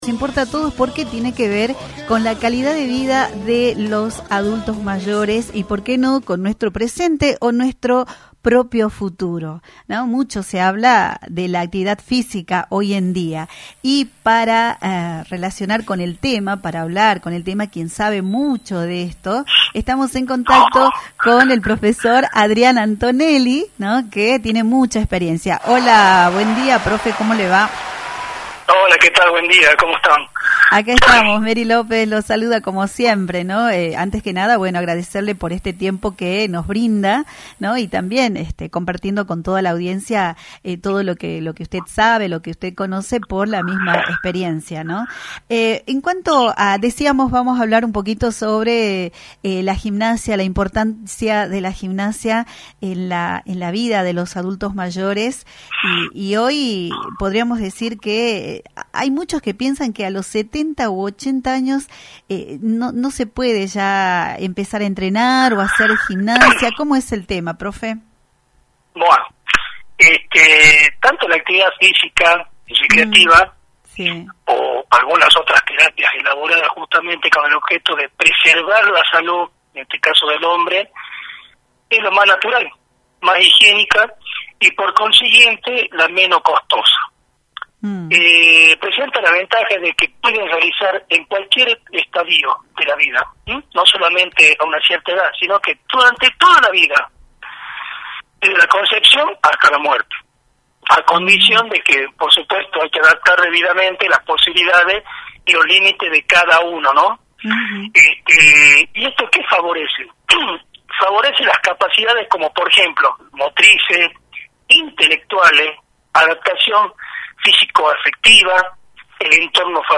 En diálogo con Máxima Mañana